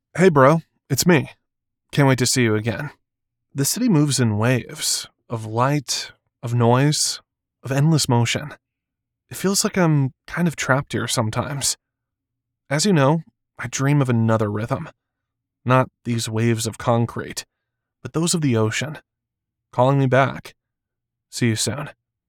Urban Regular Guy Voice Over
Words that describe my voice are young voice over, american voice over, male voice over.